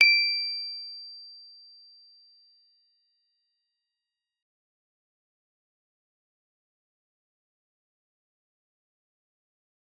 G_Musicbox-D7-f.wav